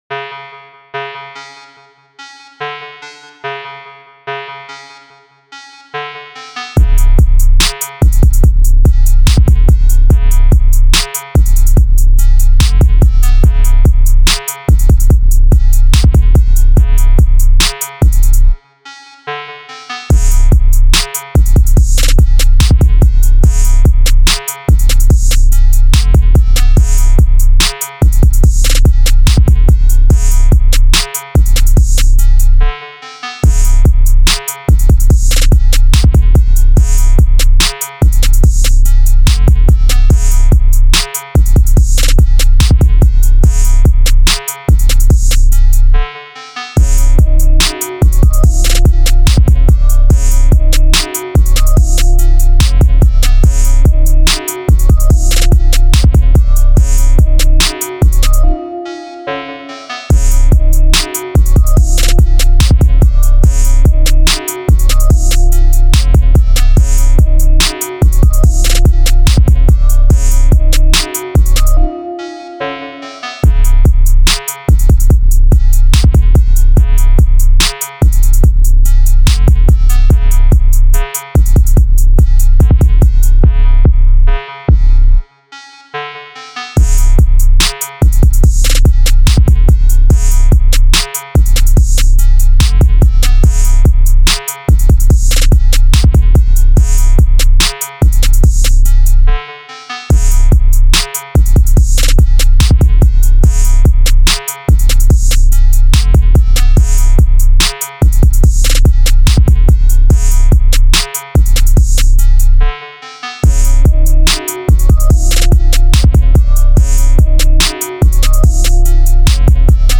2026-01-13 鼓组律动（打击）
它内置超过8000个one-shot打击乐声音，通过独特的“XO彩色点云空间”界面，用户可以直观地浏览和选择采样，快速创作出富有创意的节奏。